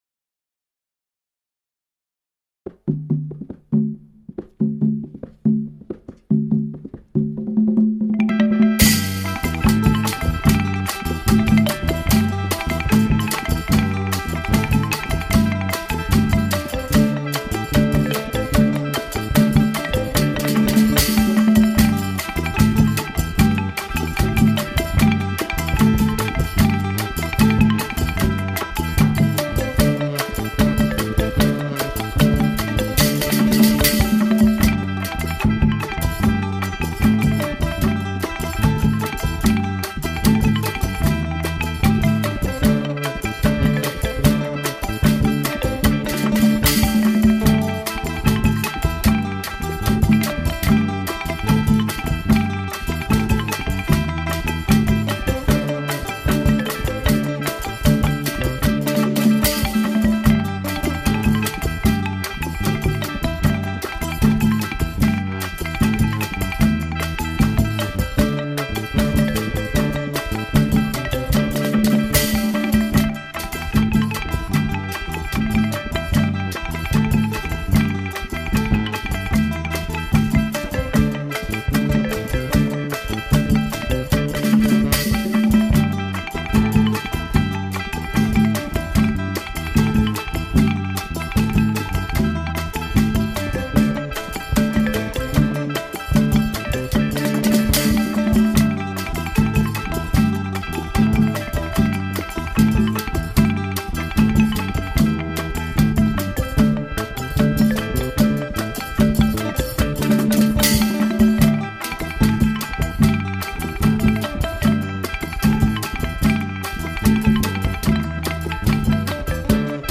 เซิ้งกระโป๋ (อีสานใต้).mp3